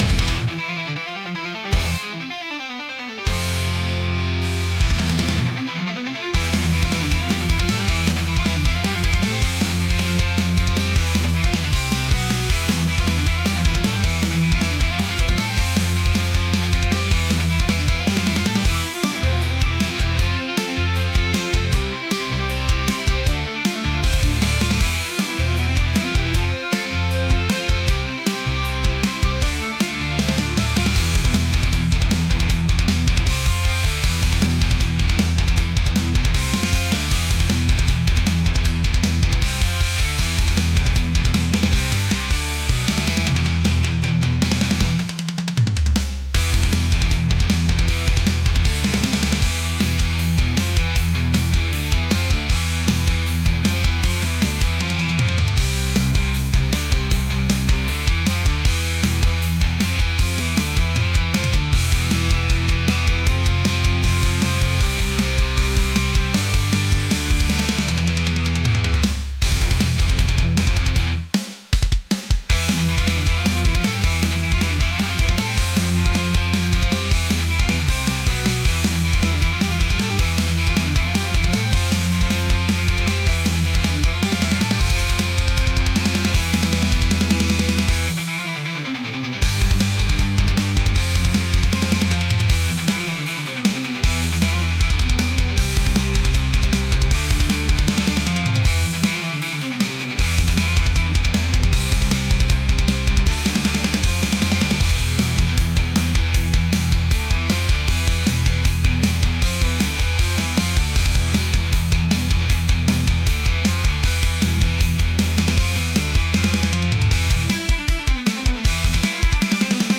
heavy | metal | aggressive